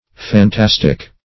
Phantastic \Phan*tas"tic\
phantastic.mp3